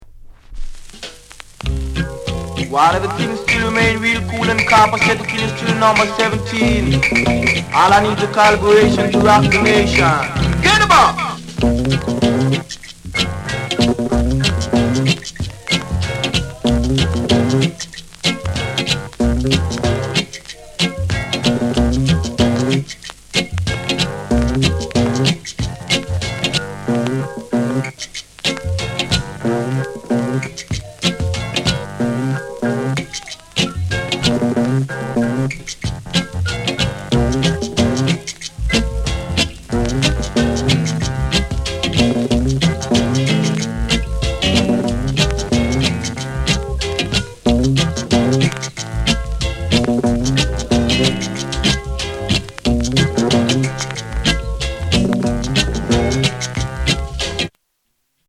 SOUND CONDITION A SIDE VG(OK)